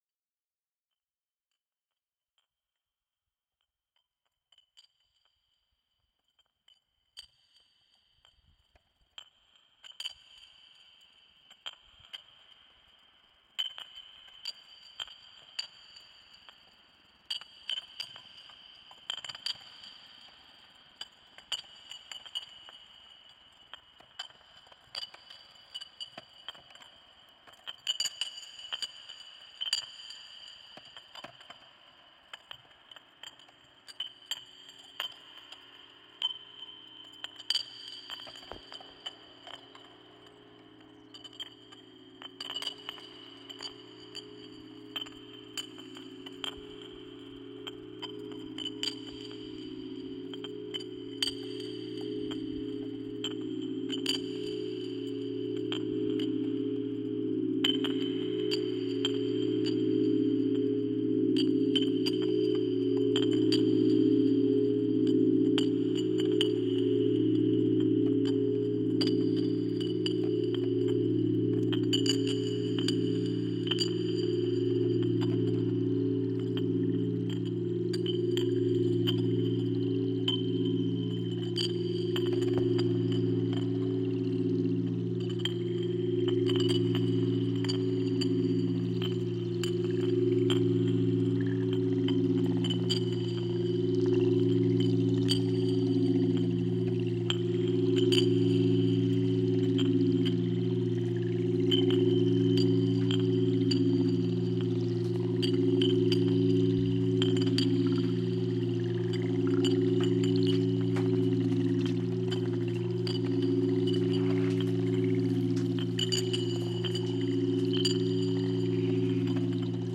en Soundscape
en Cowbells
en Nature
en Ambient